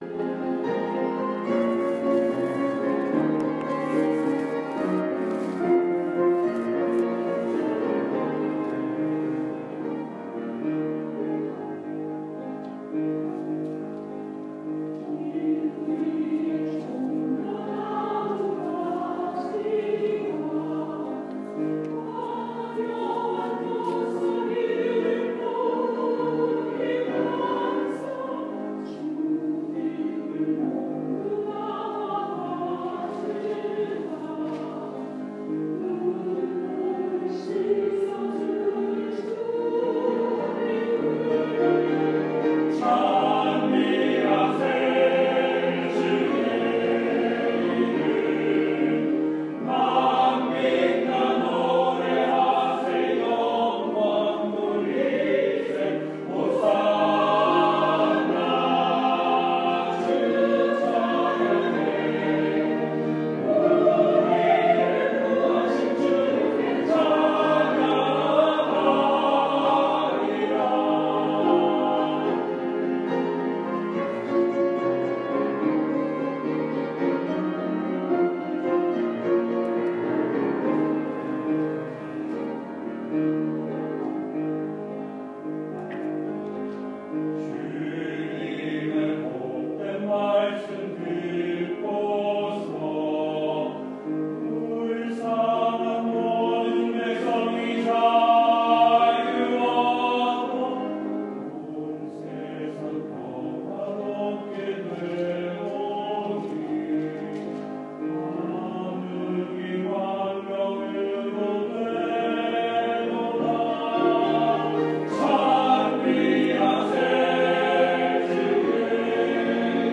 3월 20일 주일 찬양대(종려나무 The Palms) by Jean Baptiste Faure | Long Island Korean United Methodist Church | Page 3